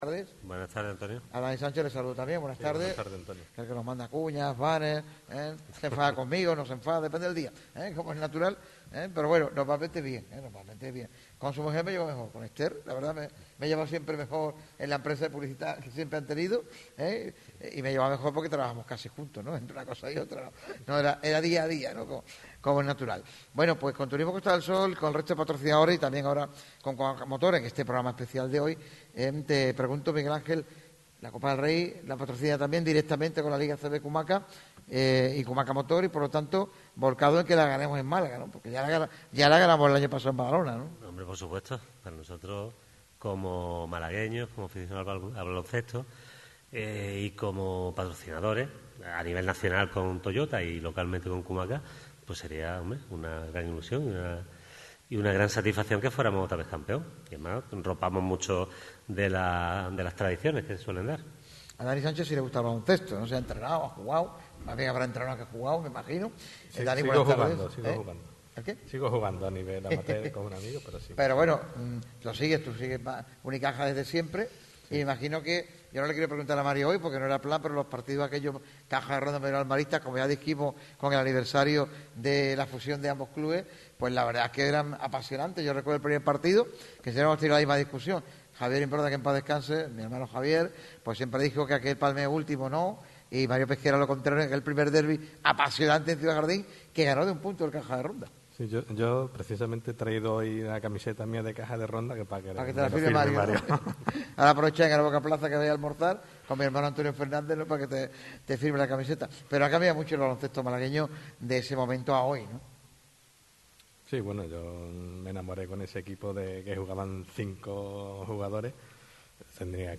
en el auditorio Edgar Neville, de la Diputación provincial de Málaga